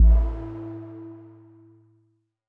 Ambient Power Down.wav